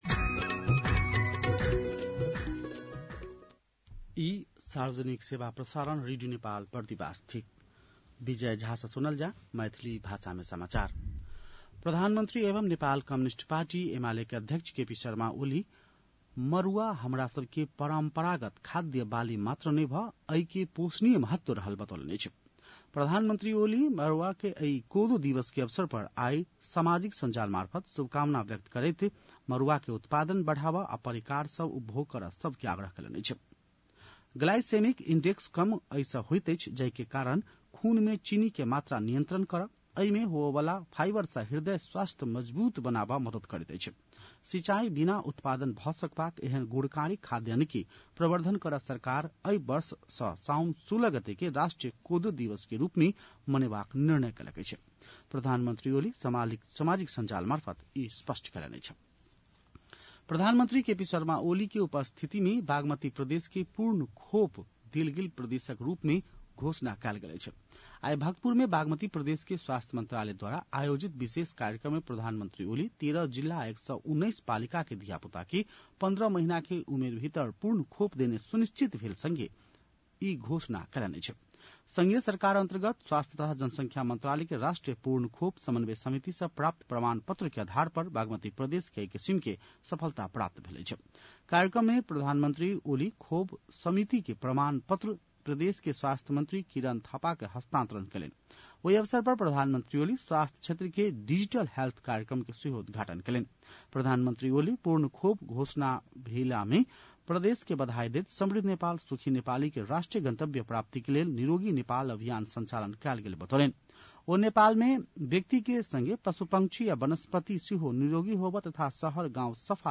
मैथिली भाषामा समाचार : १६ साउन , २०८२
Maithali-news-4-16.mp3